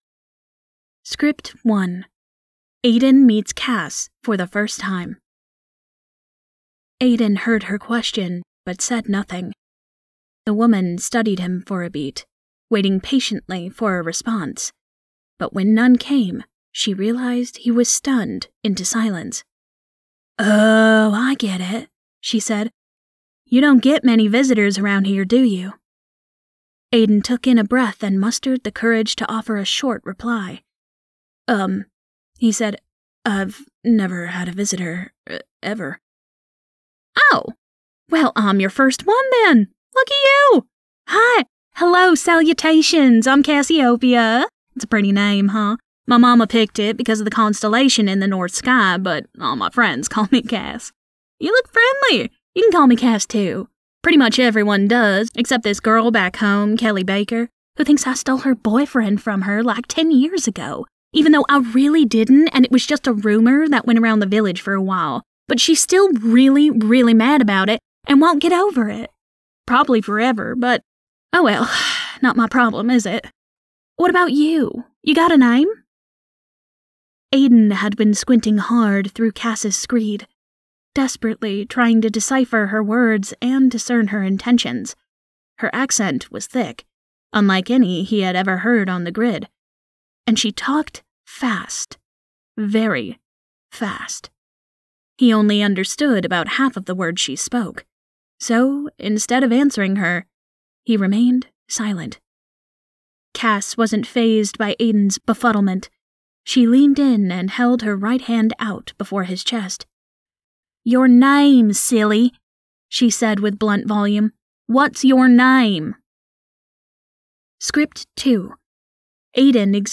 Main voice: nurturing, playful and compassionate.
Audiobooks
Gen Amer M, Texan F & Robot F
0326Texan_F__Gen_American_M__and_Robot_voice.mp3